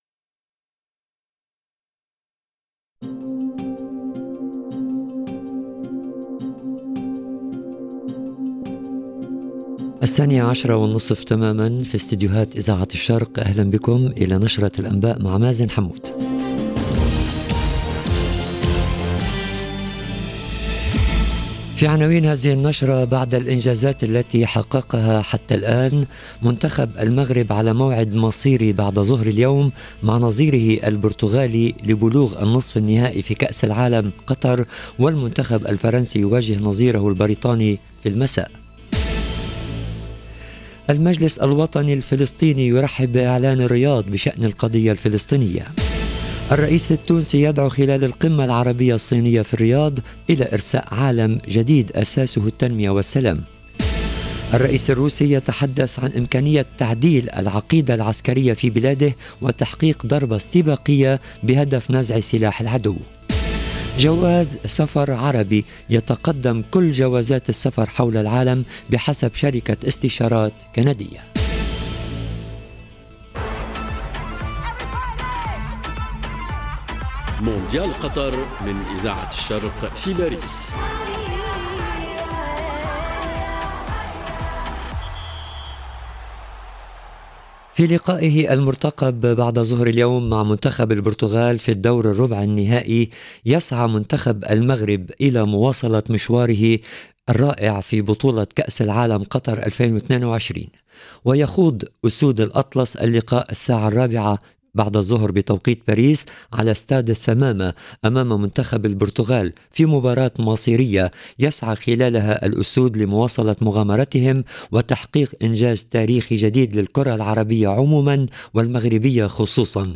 LE JOURNAL EN LANGUE ARABE DE MIDI 30 DU 10/12/22